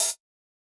UHH_ElectroHatA_Hit-16.wav